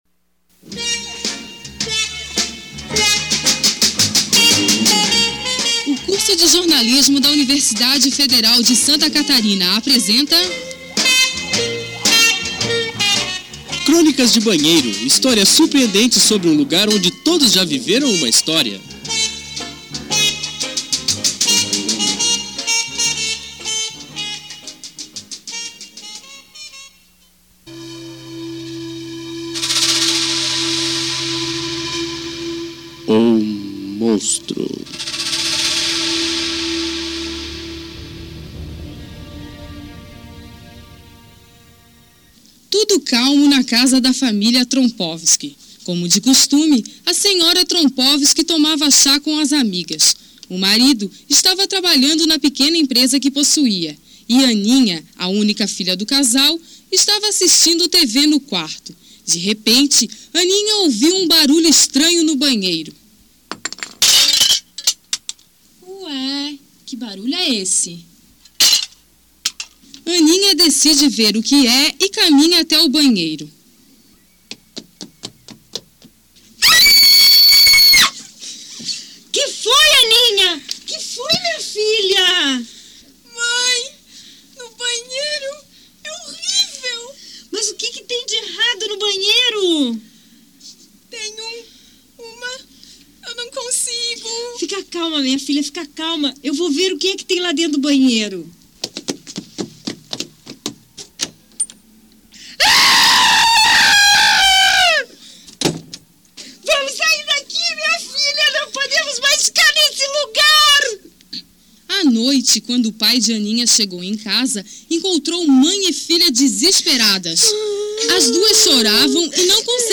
Radioteatro